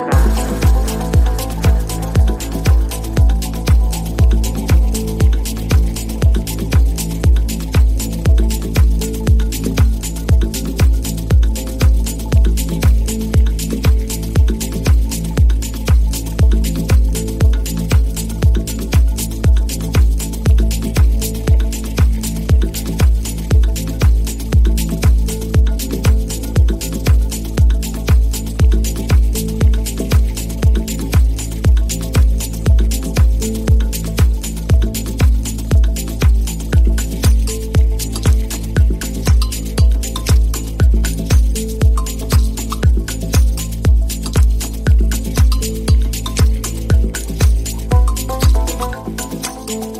دانلود اهنگ زنگ خارجی معروف